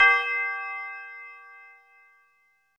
MB Tubular Bell.wav